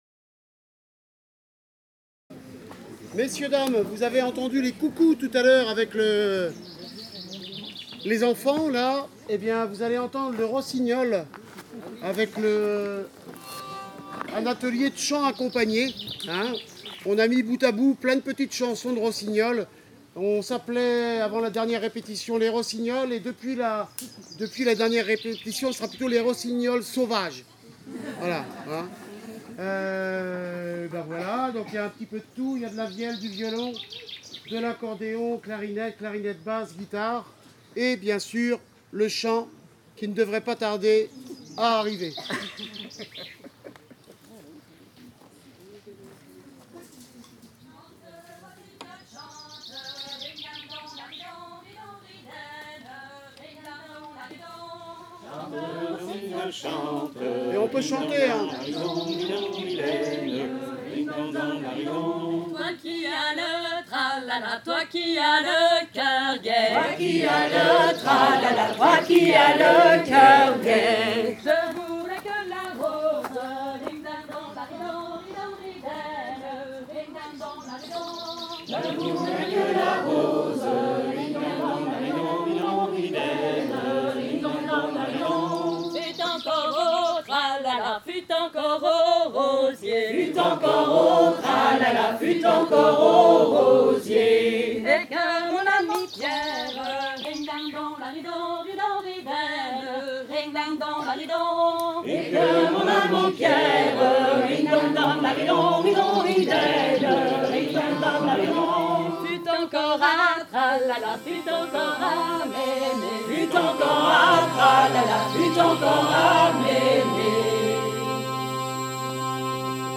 02_chant_accompagne_les_rossignolets.mp3